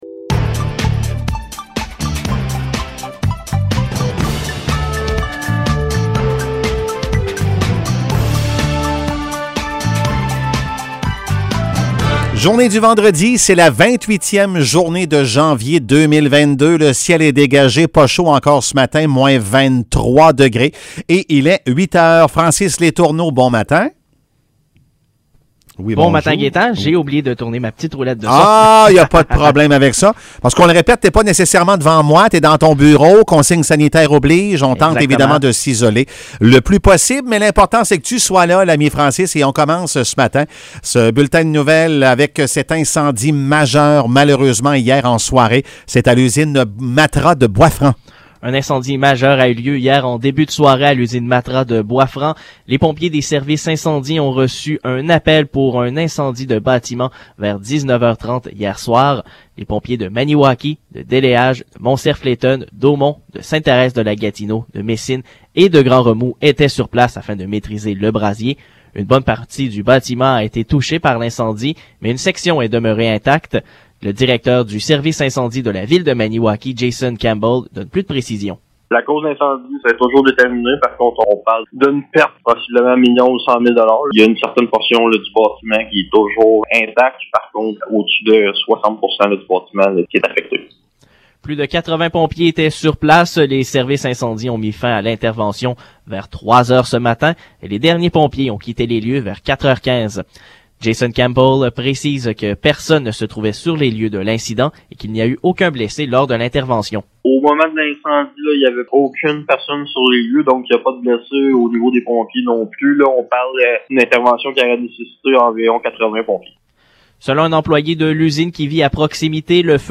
Nouvelles locales - 28 janvier 2022 - 8 h